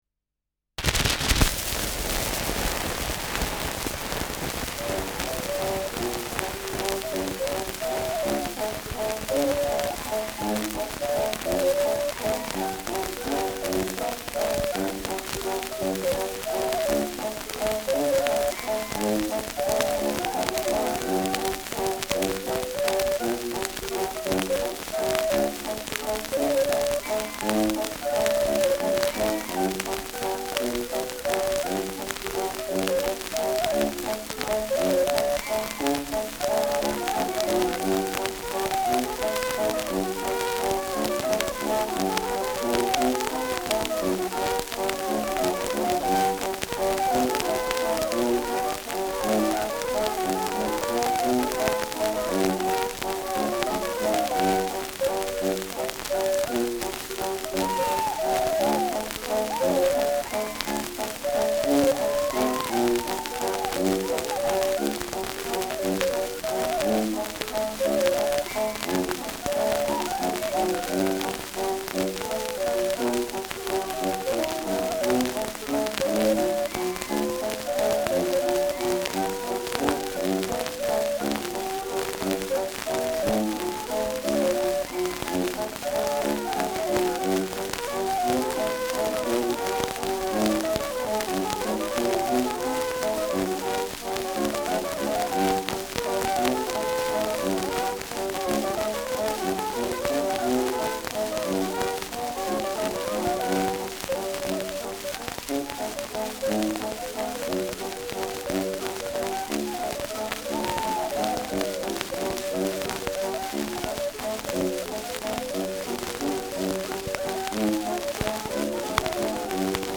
Schellackplatte
Starkes Grundrauschen : Durchgehend leichtes bis stärkeres Knacken